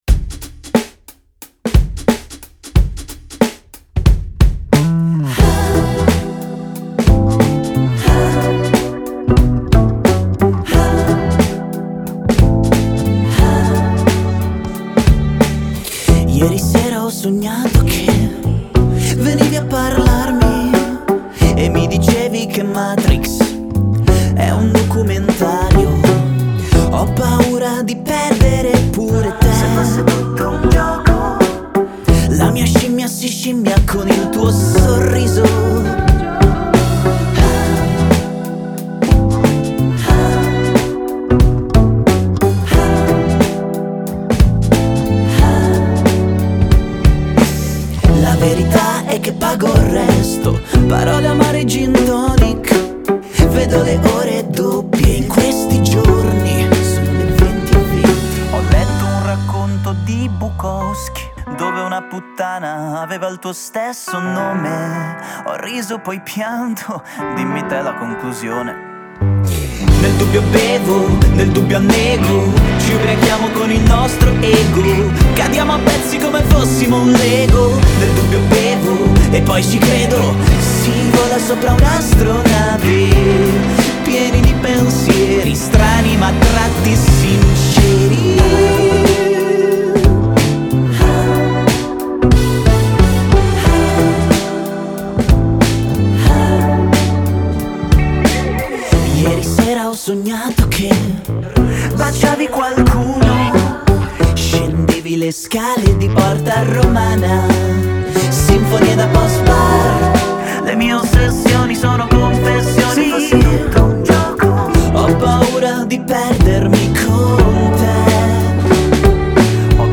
Genere: Pop.